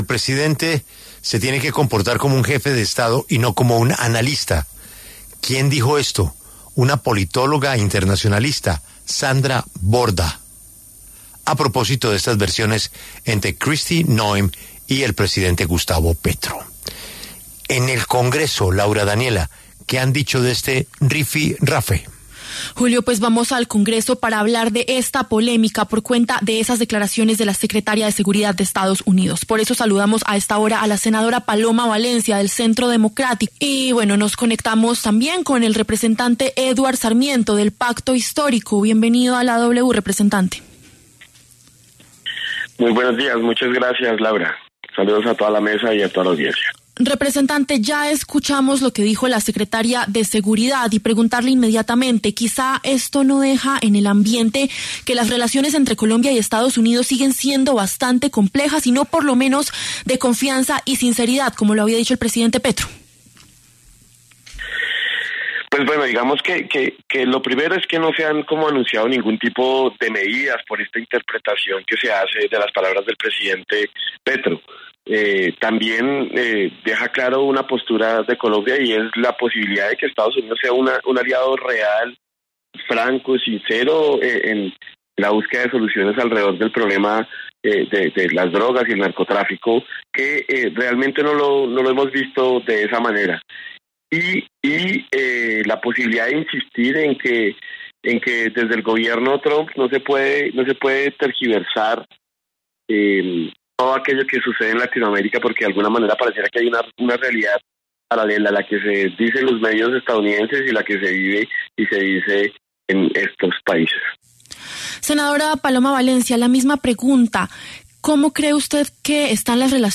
Eduard Sarmiento, representante del Pacto Histórico, y Paloma Valencia, senadora del Centro Democrático, conversaron sobre las declaraciones de la secretaria de Seguridad de EE.UU. y la respuesta del jefe de Estado colombiano.